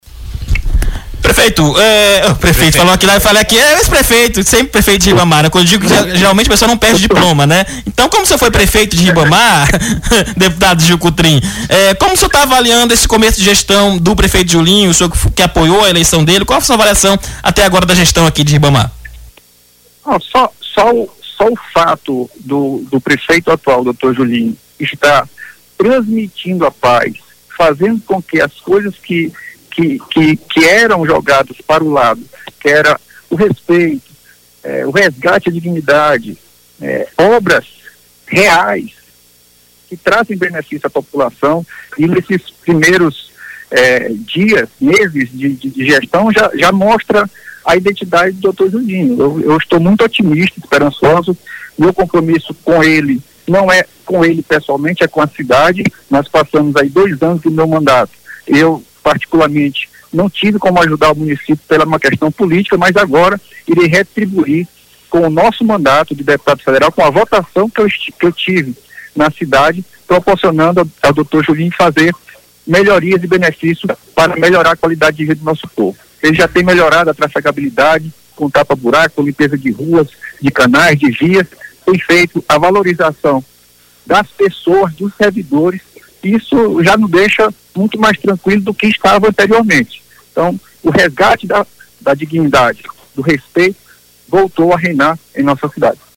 O deputado federal Gil Cutrim (PDT) destacou, em entrevista à Rádio Mais FM (99.9 MHz), que o prefeito de São José de Ribamar, Júlio Matos – o Dr. Julinho (PL), ‘resgatou’ a dignidade e com ele o respeito voltou a reinar na cidade ribamarense.